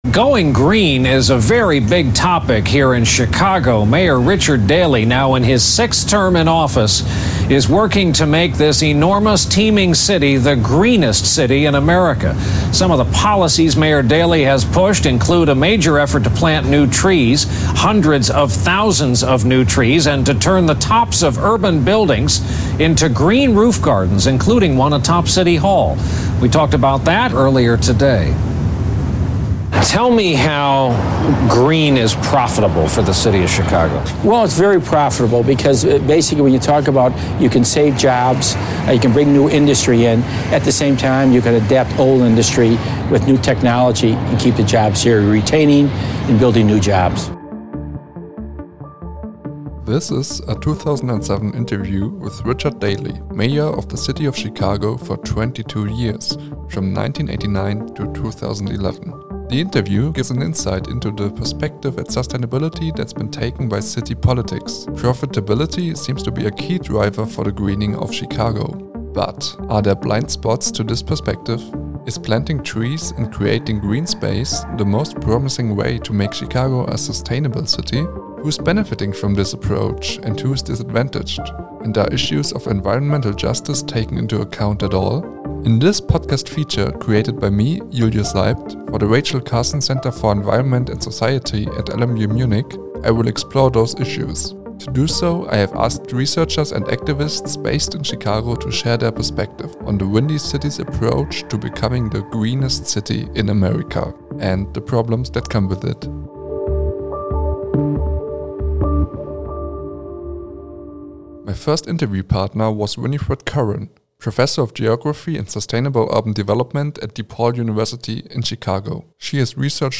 This podcast investigates on-the-ground issues of environmental inustices and green gentrification in Chicago. In the research process, by far the most valuable sources were interviews conducted with researchers and activists living and working in Chicago.